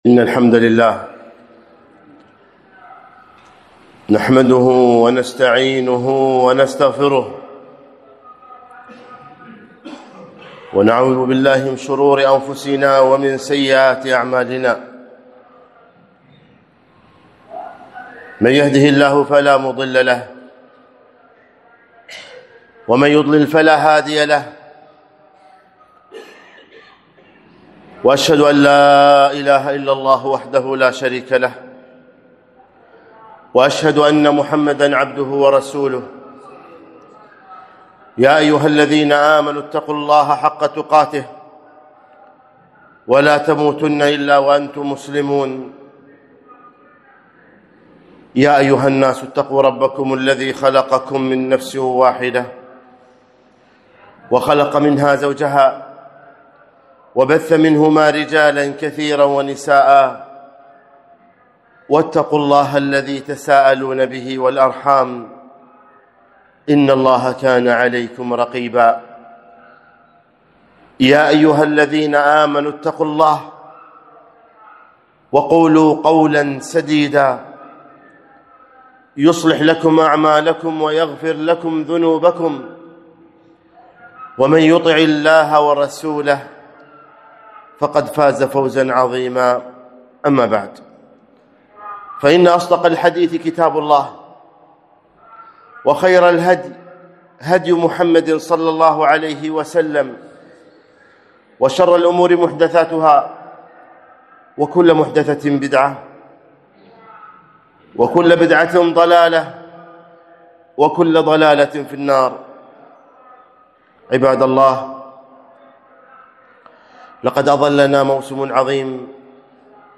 خطبة - جاءكم شهر رمضان